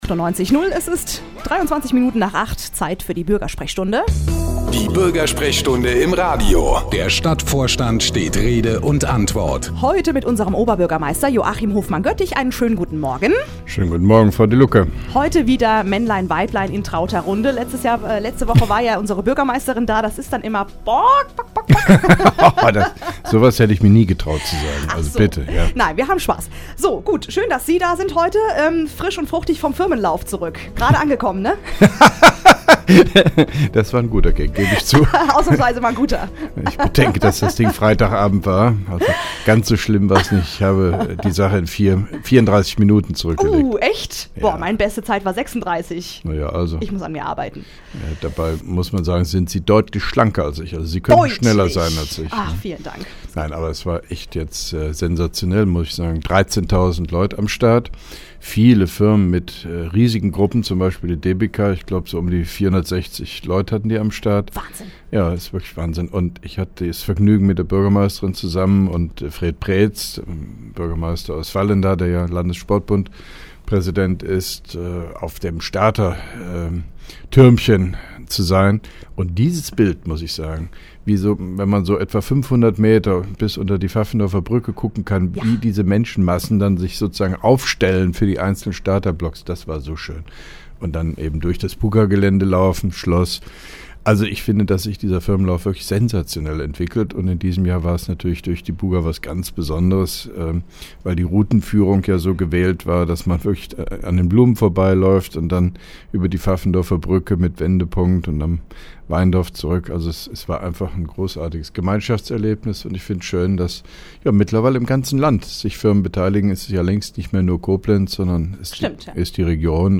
(1) Koblenzer Radio-Bürgersprechstunde mit OB Hofmann-Göttig 21.06.2011